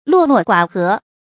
落落寡合 luò luò guǎ hé 成语解释 落落：孤独的样子；不合群。
成语简拼 llgh 成语注音 ㄌㄨㄛˋ ㄌㄨㄛˋ ㄍㄨㄚˇ ㄏㄜˊ 常用程度 常用成语 感情色彩 中性成语 成语用法 偏正式；作谓语、定语；含贬义 成语结构 偏正式成语 产生年代 近代成语 成语正音 落，不能读作“là”。